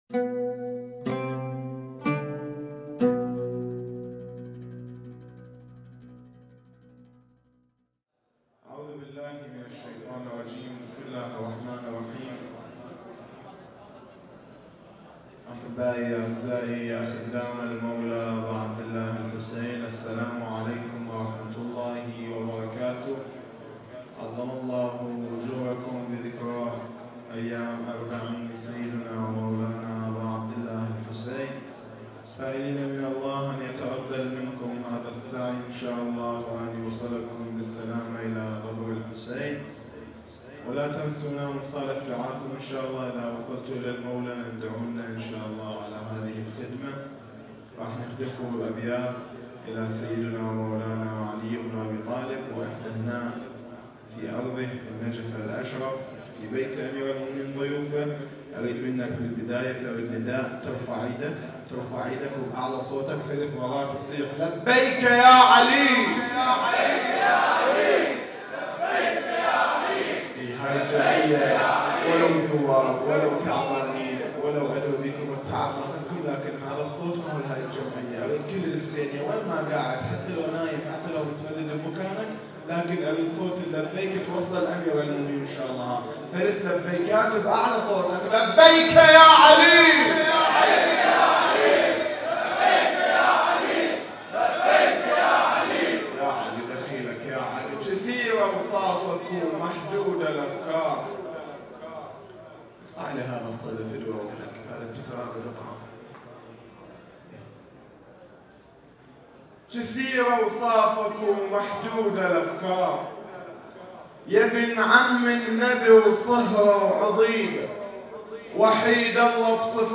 اجتماع الزوار العرب فی الاربعین
نجف الاشرف، بیت العلوی